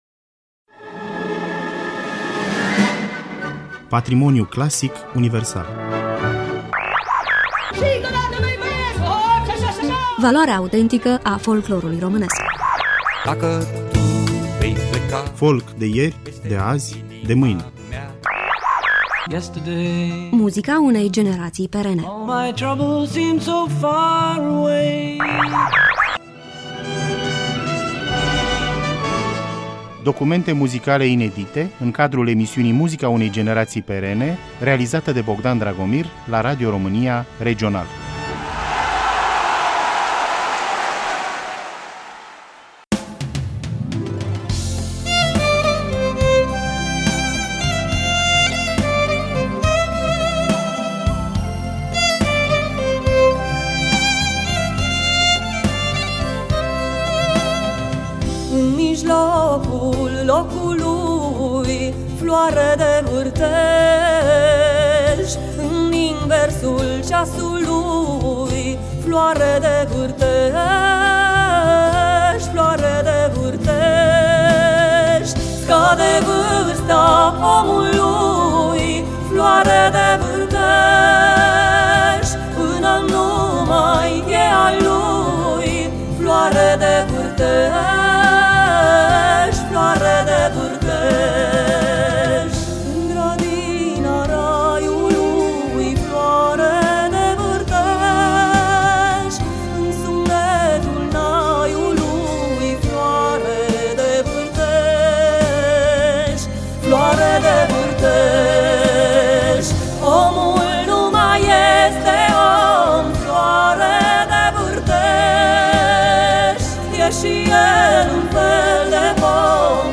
Astăzi ne vom opri la muzica folk și invitata muzicală a acestei rubrici este MARIA GHEORGHIU, căreia îi dorim cu drag LA MULȚI ANI, împreună cu cele mai bune gânduri!